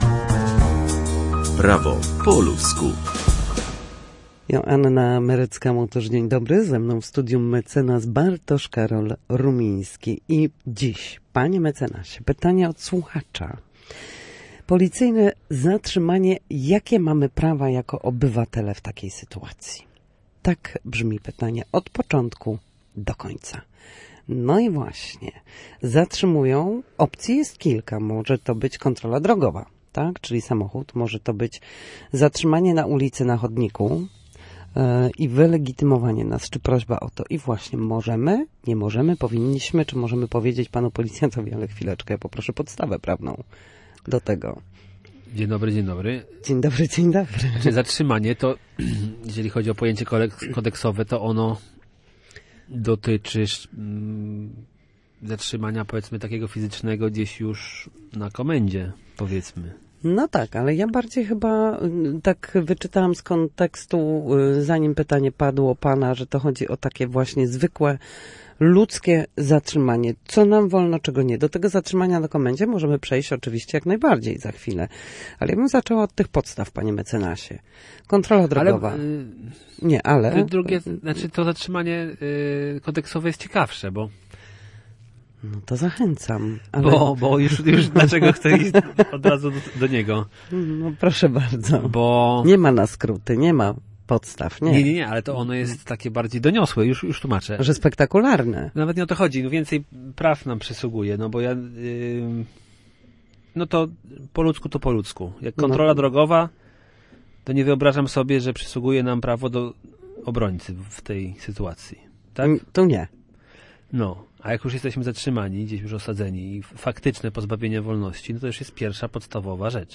W każdy wtorek o godzinie 13:40 na antenie Studia Słupsk przybliżamy meandry prawa. Nasi goście, prawnicy, odpowiadają na jedno pytanie dotyczące zachowań w sądzie lub podstawowych zagadnień prawniczych.